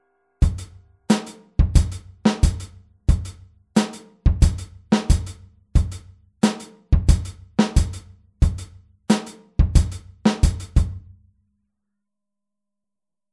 Nach dem 4tel-Offbeat setzen wir ein Echo hinzu
Hier spielen wir den Offbeat mit der rechten Hand wieder auf dem HiHat.